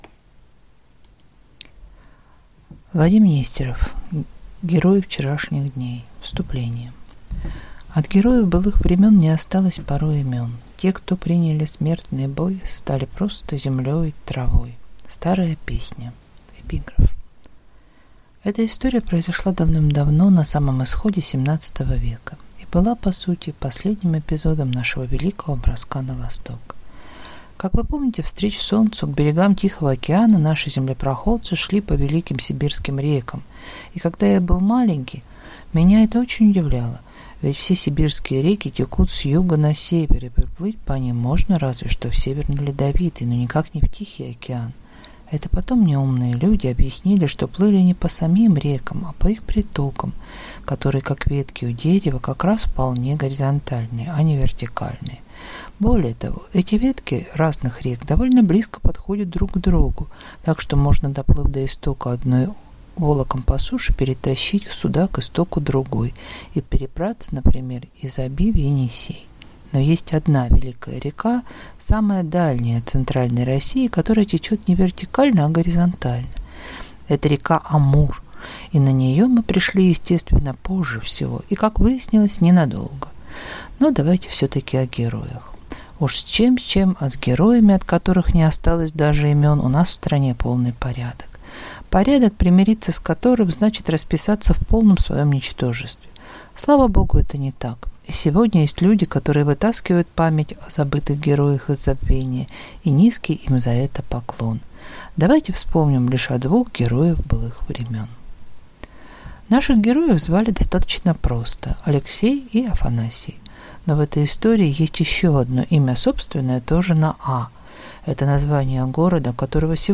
Аудиокнига Герои вчерашних дней | Библиотека аудиокниг